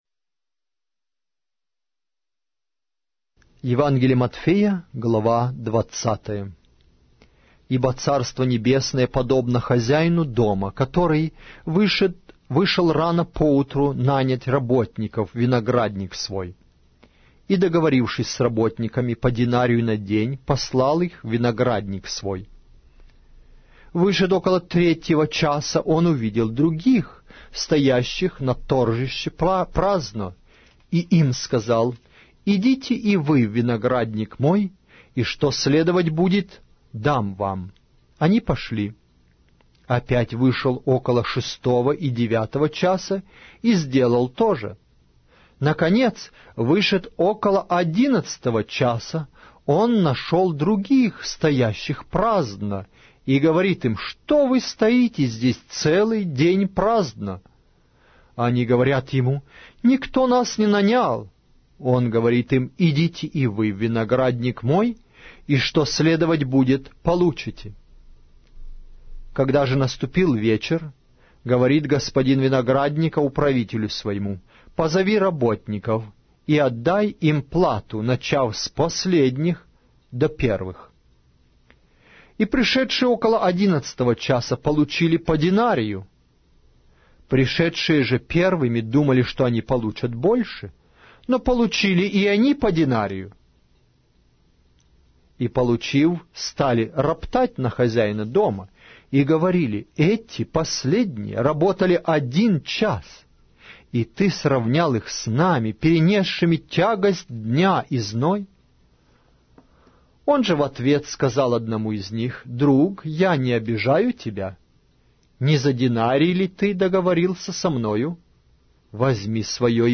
Аудиокнига: Евангелие от Матфея